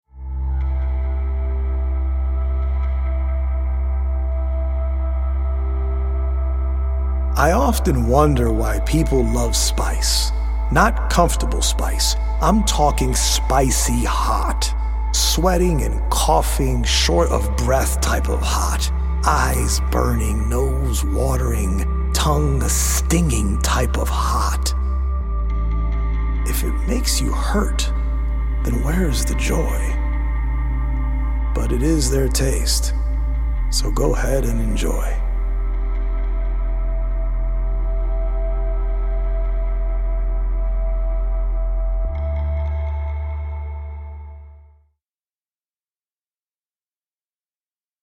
healing Solfeggio frequency music